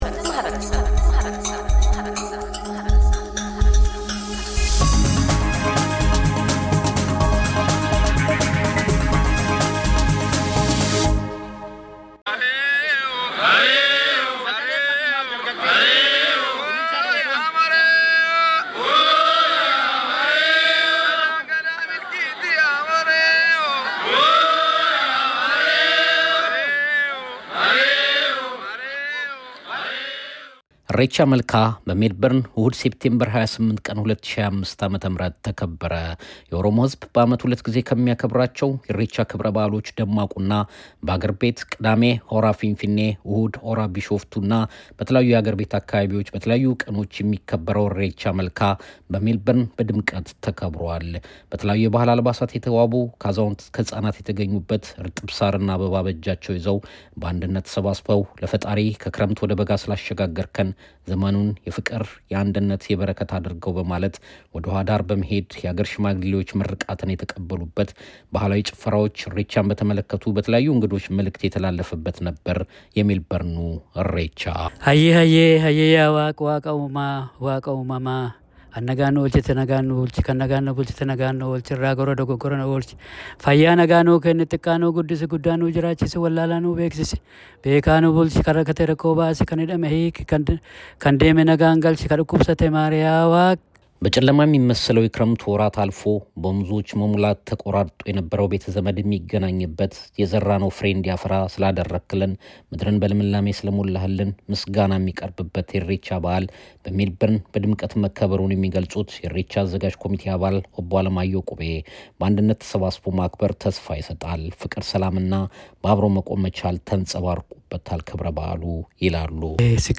የኢሬቻ መልካ 2018 / 2025 ክብረ በዓል እሑድ መስከረም 18 / ሴፕቴምበር 28 በ Wilson Botanic Park Berwick ሜልበርን ተከብሮ ውሏል። ታዳሚዎች የበዓሉን አከባበር አስመልክተው አተያዮቻቸውን ያጋራሉ። መልካም ምኞቶቻቸውንም ይገልጣሉ።